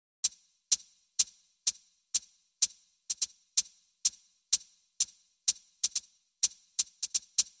Хай-хэт — один из ключевых элементов ударной установки, создающий яркие и ритмичные звуки.
Хай хэт - Услышьте этот звук